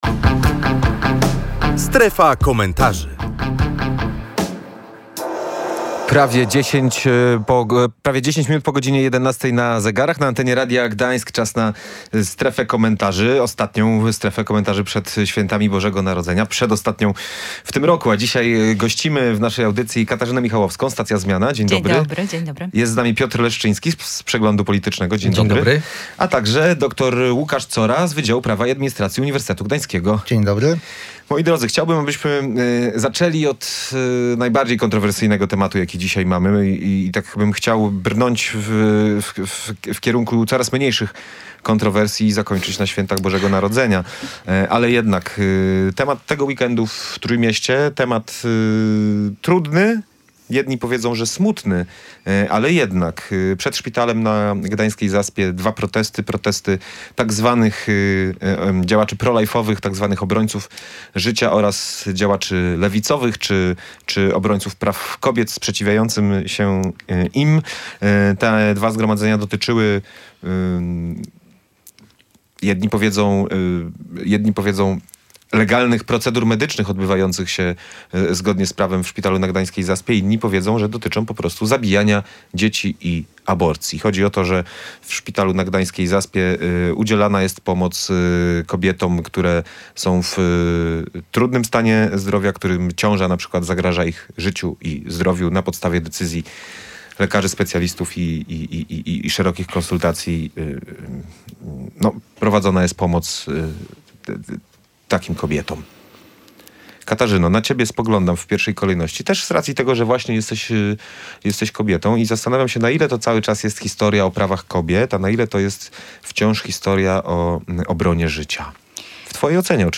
Oba zgromadzenia dotyczyły aborcji, którą jedna strona nazywa legalną procedurą medyczną, a druga – zabijaniem dzieci. Między innymi o tym rozmawialiśmy w „Strefie Komentarzy”.